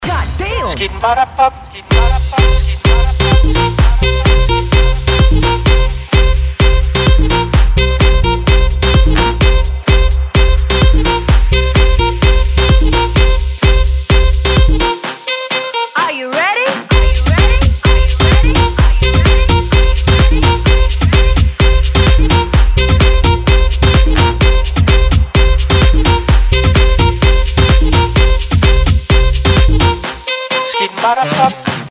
• Качество: 192, Stereo
Ремикс популярной песни